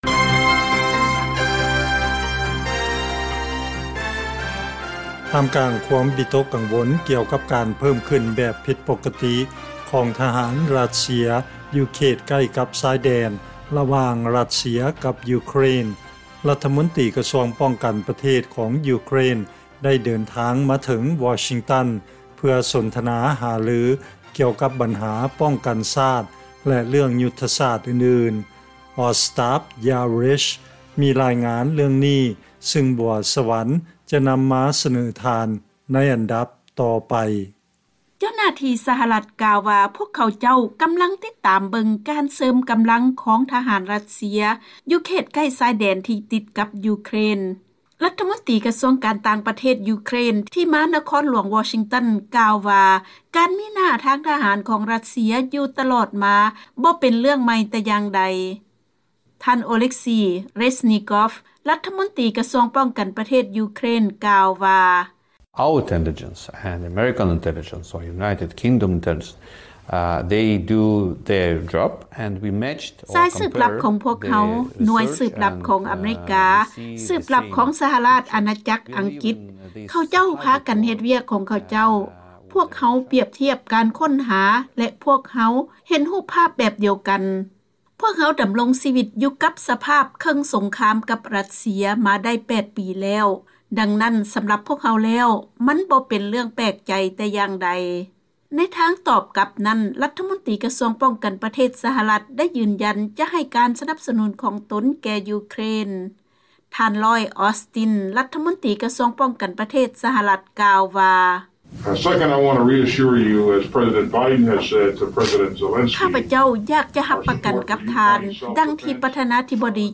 ເຊີນຟັງລາຍງານກ່ຽວກັບການປຶກສາຫາລືກັນຂອງ ສຫລ ແລະຢູເຄຣນໃນເລື້ອງຣັດເຊຍ